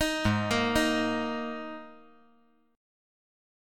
Absus2 Chord
Listen to Absus2 strummed